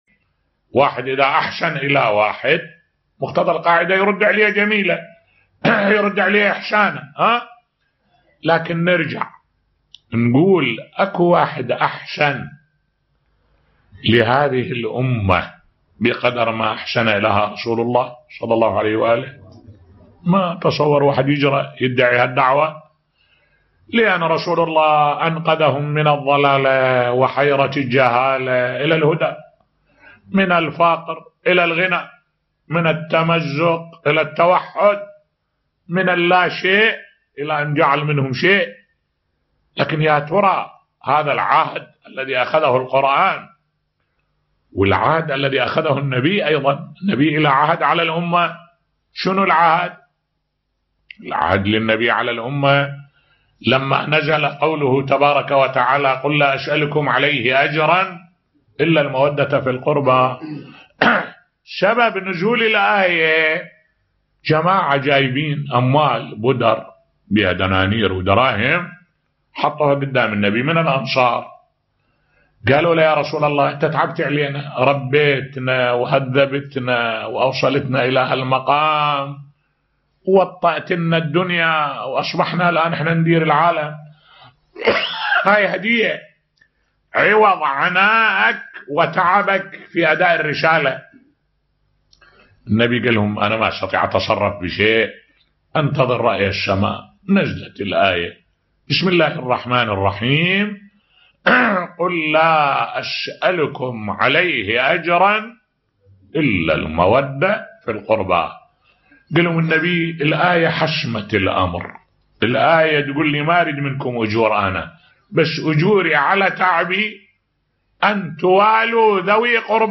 ملف صوتی الأجر الذي طلبه رسول الله على رسالته بصوت الشيخ الدكتور أحمد الوائلي